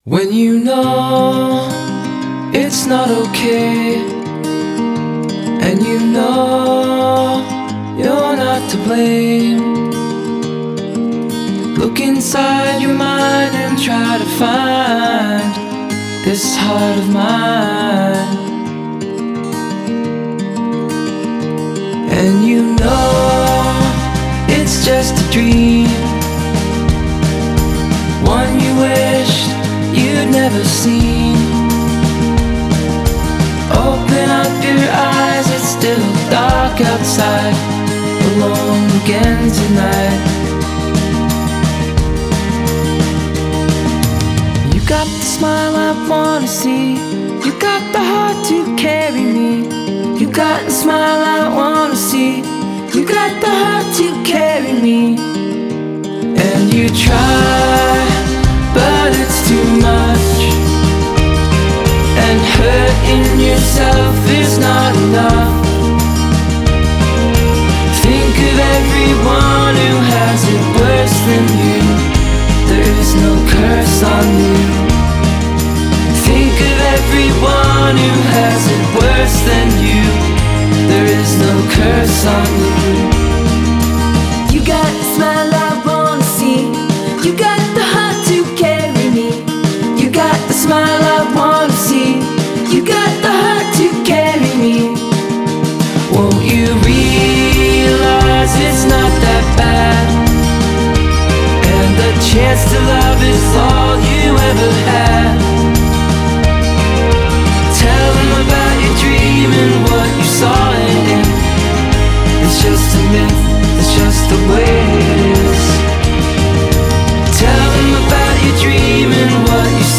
Genre: Indie Pop, Indie Folk